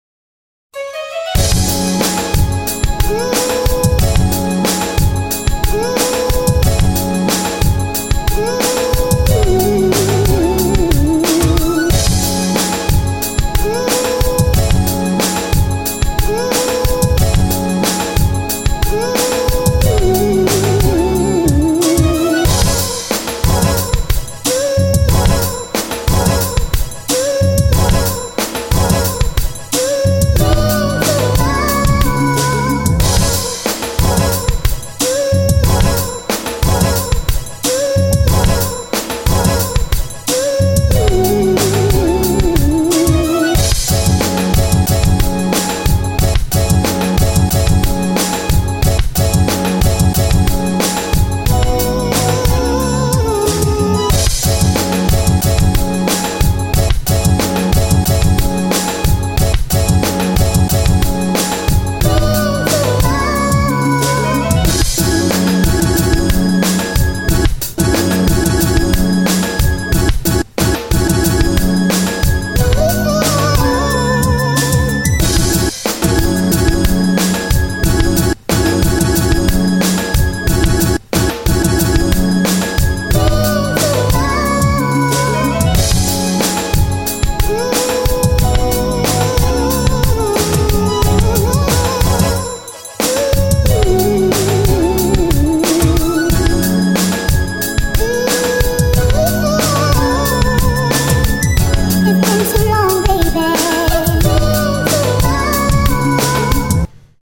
드럼이 좀 더 착 달라붙는 쪽이었어도 좋았을 것 같습니다 ㅎㅎ 샘플 컷 멋져요!